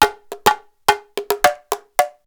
PERC 30.AI.wav